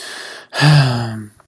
sigh.ogg.mp3